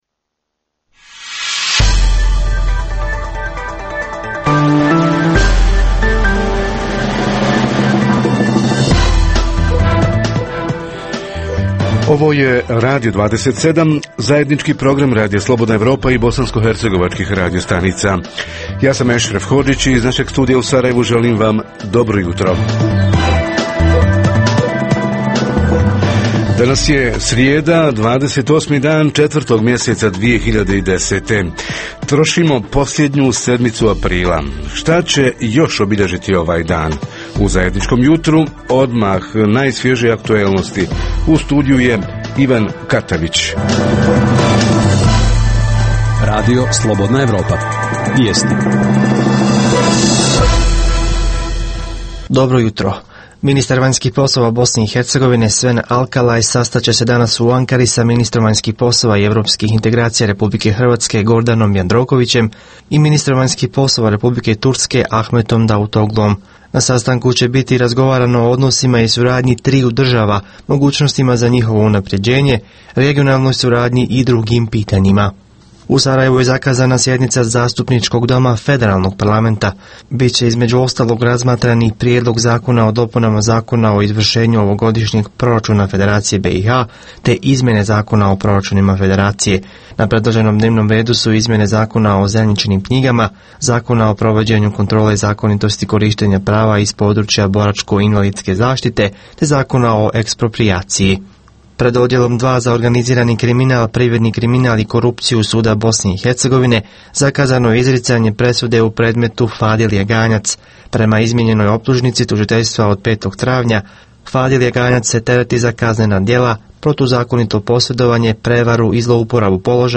Aktivizam mladih – koje projekte srednjoškolci žele realizirati u svojim lokalnim zajednicama? Reporteri iz cijele BiH javljaju o najaktuelnijim događajima u njihovim sredinama.